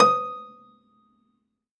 53p-pno15-D3.wav